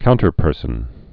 (kountər-pûrsən)